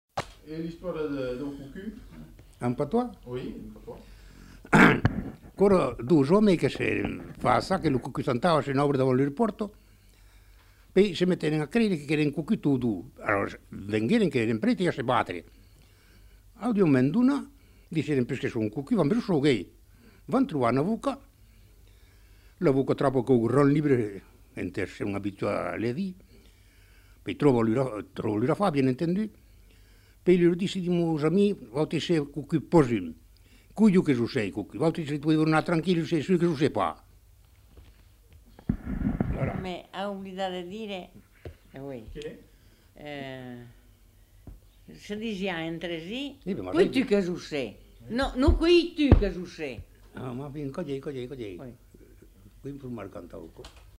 Aire culturelle : Périgord
Lieu : Saint-Rémy-de-Gurson
Genre : conte-légende-récit
Type de voix : voix d'homme
Production du son : parlé